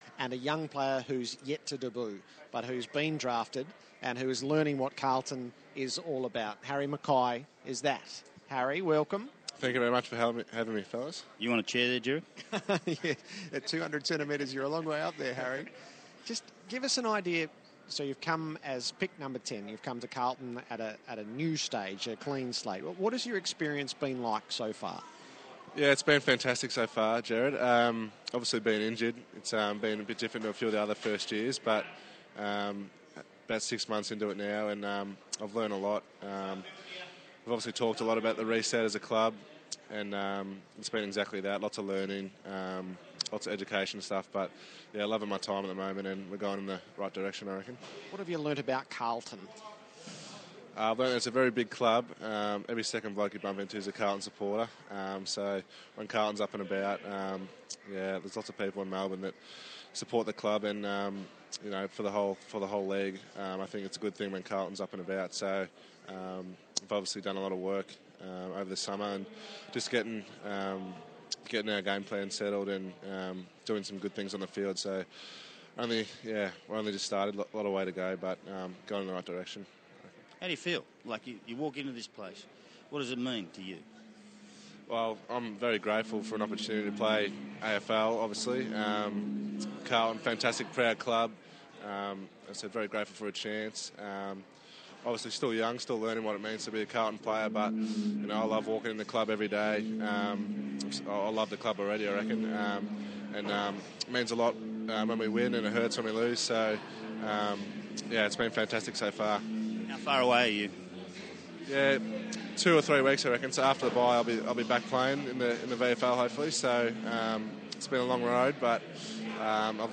Injured Carlton forward Harry McKay speaks with ABC AFL's Mark Maclure and Gerard Whateley in the rooms before the Blues' clash against Brisbane.